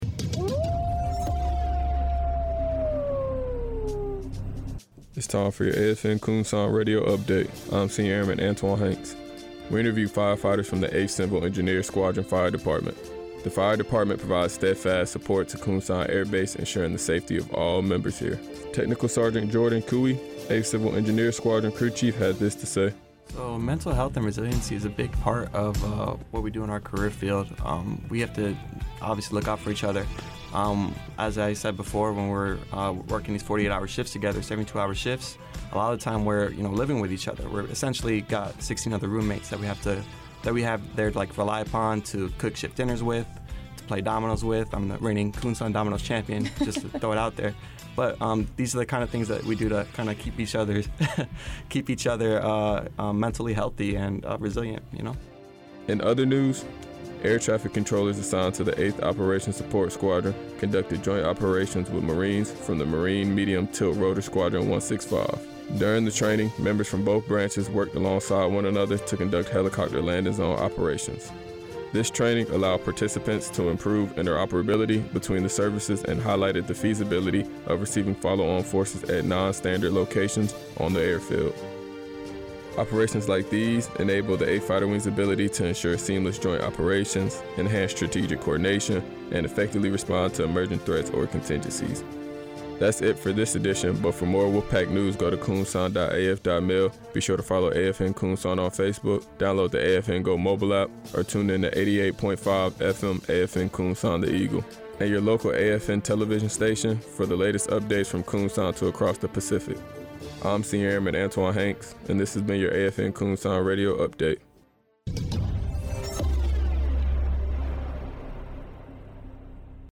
This AFN Kunsan Radio Update covers the recent joint helicopter landing zone operations by the Marine Medium Tilt Rotor Squadron 165 and 8th Operations Support Squadron. Additionally, members assigned to the 8th Civil Engineer Squadron Fire Department at Kunsan Air Base discuss how they maintain positive mental health and resiliency while being first responders.